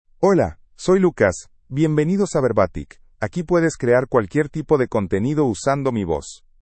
Lucas — Male Spanish (United States) AI Voice | TTS, Voice Cloning & Video | Verbatik AI
MaleSpanish (United States)
Lucas is a male AI voice for Spanish (United States).
Voice sample
Lucas delivers clear pronunciation with authentic United States Spanish intonation, making your content sound professionally produced.